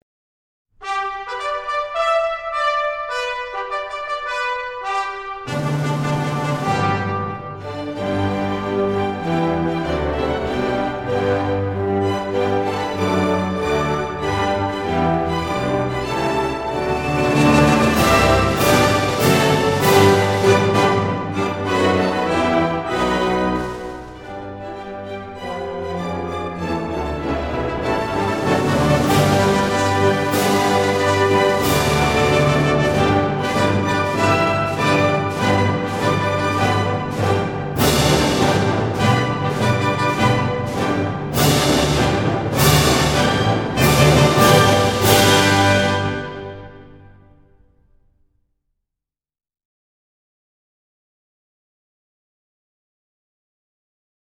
中国管弦乐及小品
这张精心制作的专辑在录音、数码处理上均为上乘，既体现出管弦乐恢弘的壮丽，也体现出民乐婉转的悠扬。
弦乐器丝绸般的柔滑、管乐器光泽般的脆亮、鼓乐器礼炮般的厚重都表明该碟的分析力、空间感、动态等都有不俗表现。